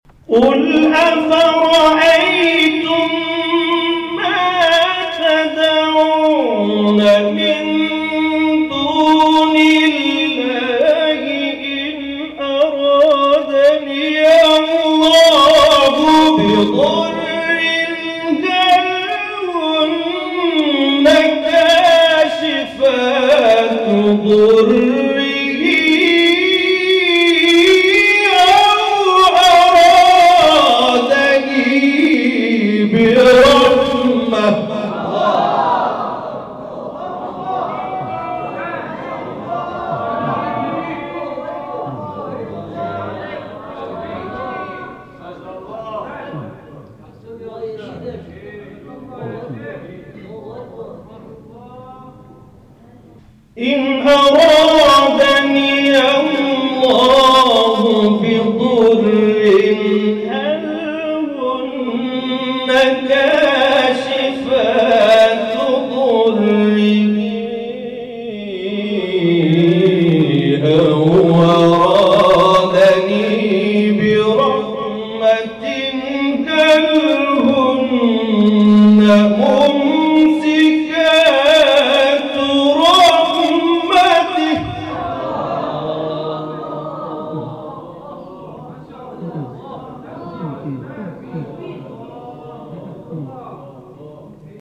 گروه فعالیت‌های قرآنی: فرازهای صوتی از قاریان ممتاز کشور ارائه می‌شود.
در مقام سه گاه